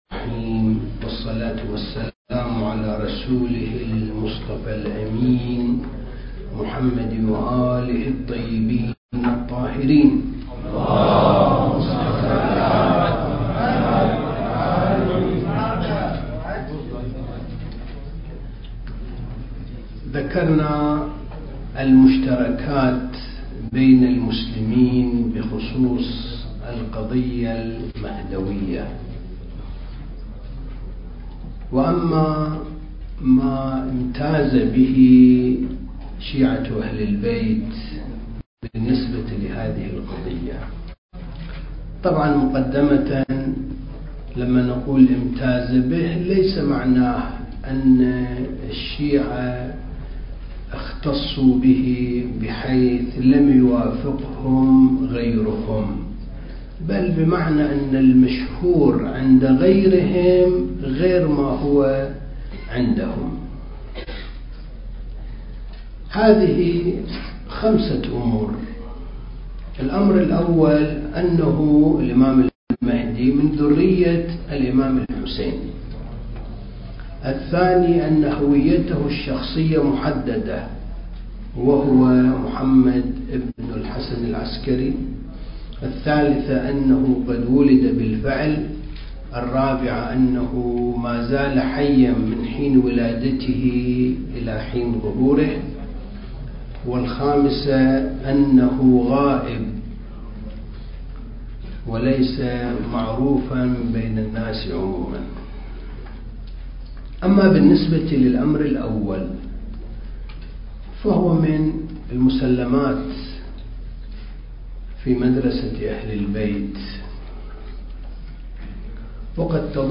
دورة الثقافة المهدوية (4) المكان: معهد وارث الأنبياء (عليهم السلام) لإعداد المبلغين العتبة الحسينية المقدسة